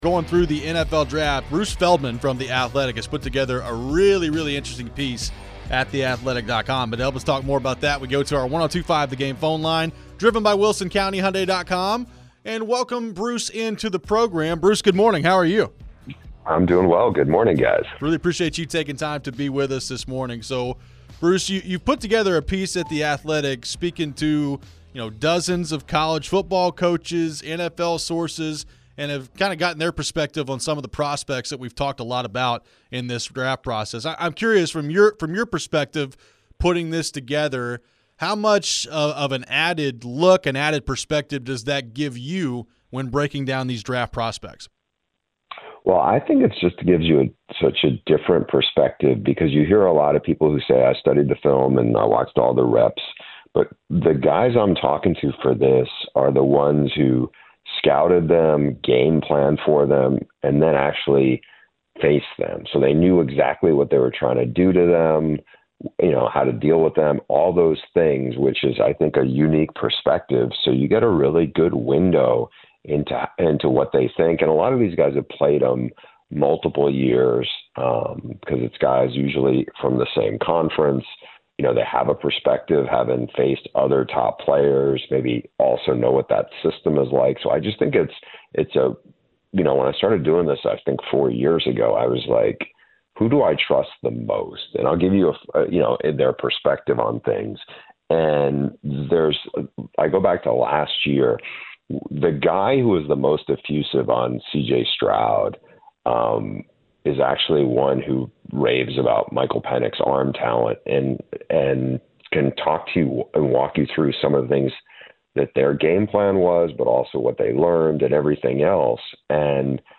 Bruce Feldman Interview (4-8-24)
Bruce Feldman from The Athletic joins the show to talk about the NFL Draft.